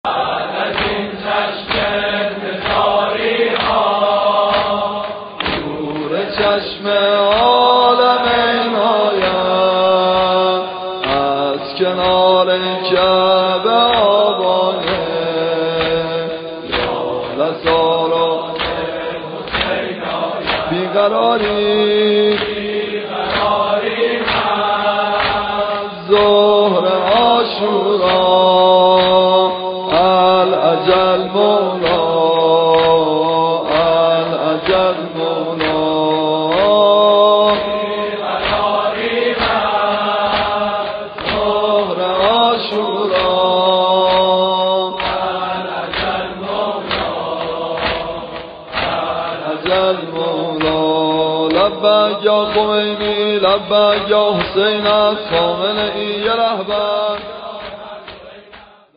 واحد | بعد از این چشم انتظاری‌ها نور چشم عالمین آید
مداحی